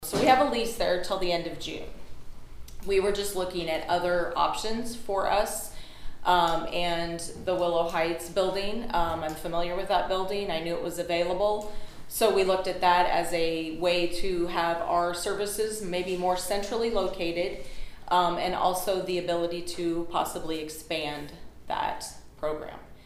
(Cass Co.) The lease agreement for the old Willow Heights residential facility was the main topic of discussion at the Cass County Board of Supervisors meeting again this morning.